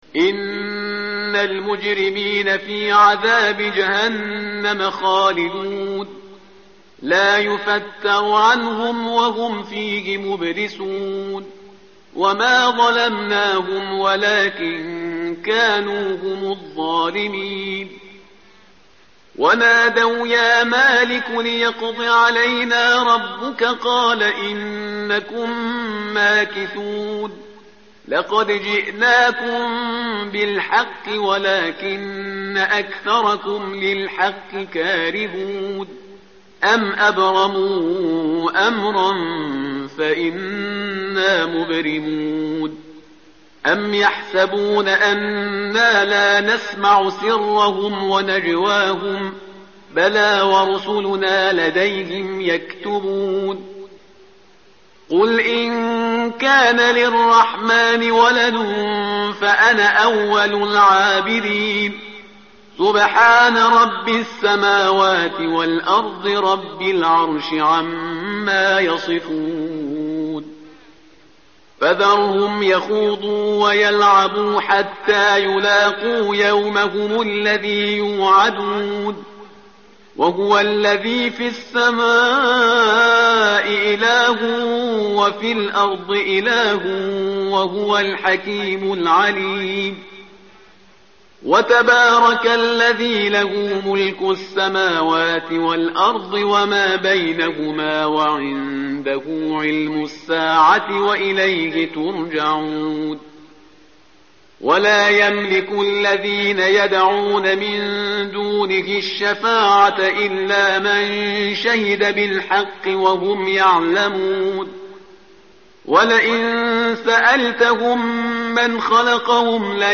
متن قرآن همراه باتلاوت قرآن و ترجمه
tartil_parhizgar_page_495.mp3